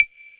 TI100CLAV1-R.wav